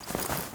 SFX_InvOpen_01_Reverb.wav